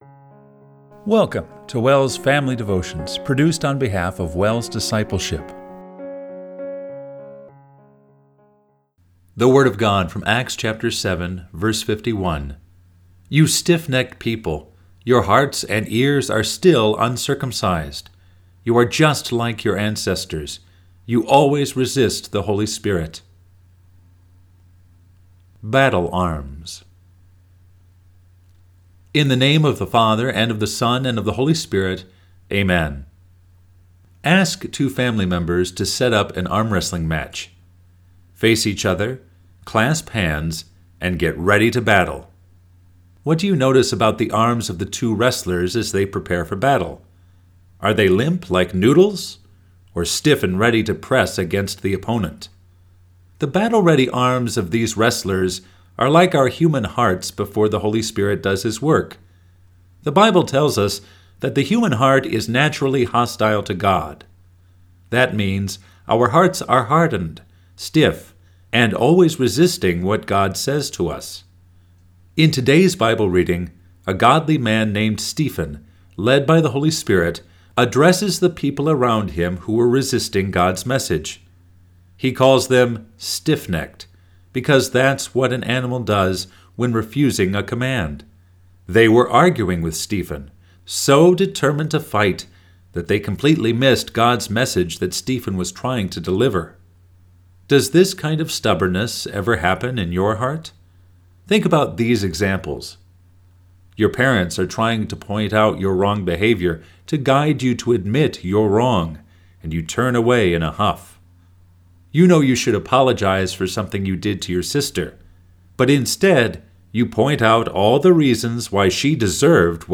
Family Devotion – May 13, 2024